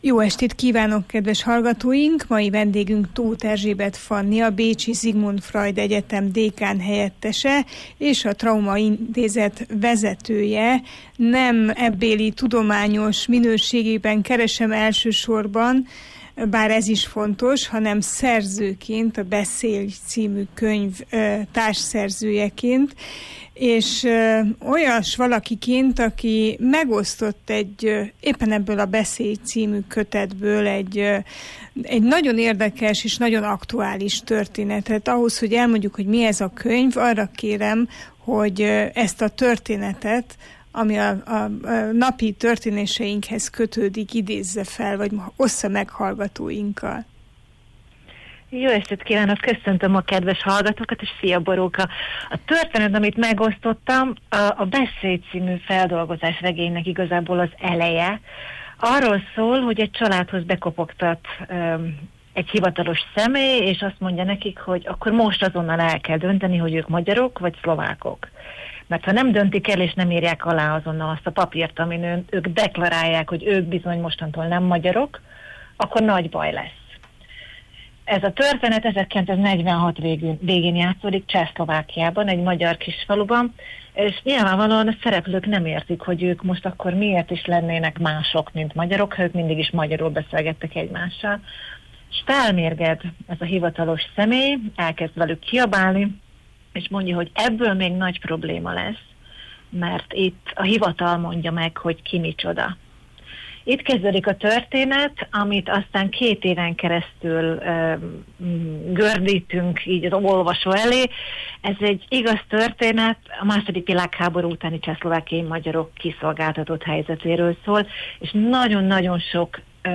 Az Értsünk Szót adásában erről beszélgettünk.